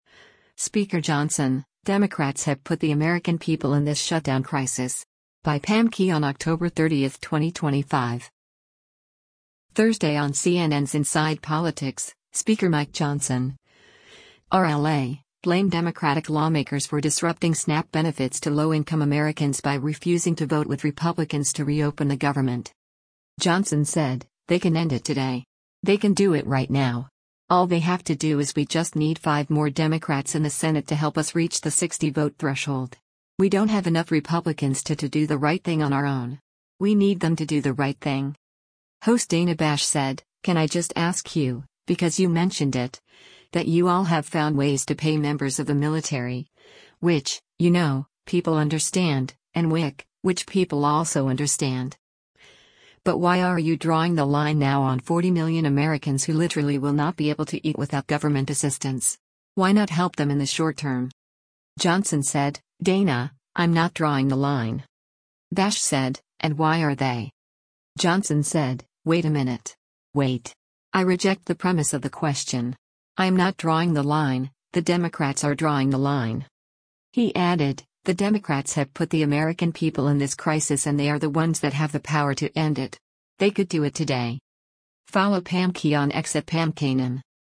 Thursday on CNN’s “Inside Politics,” Speaker Mike Johnson (R-LA) blamed Democratic lawmakers for disrupting SNAP benefits to low-income Americans by refusing to vote with Republicans to reopen the government.